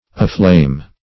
Aflame \A*flame"\ ([.a]*fl[=a]m"), adv. & a. [Pref. a- + flame.]